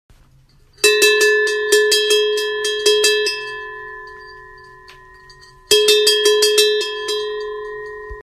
Cloche suisse traditionnelle de vache, avec edelweiss riveté
SON DE LA CLOCHE    Son bruit de cloche vache suisse
Le son de la cloche peut varier sensiblement entre les tailles de cloches.